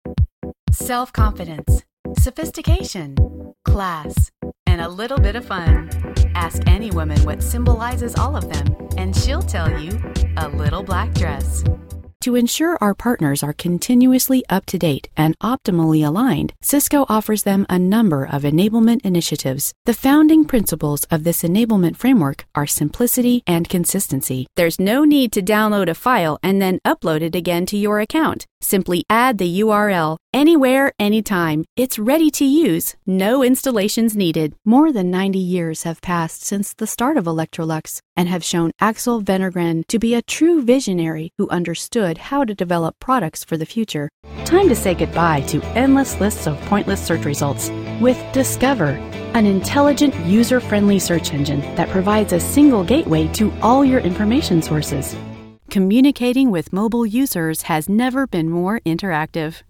Female Voice Over Talent